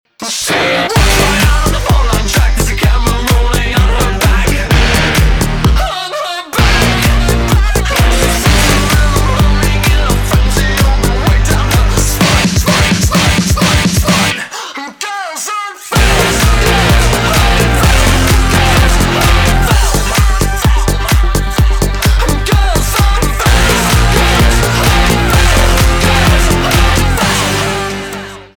рок
гитара , барабаны , жесткие , качающие , громкие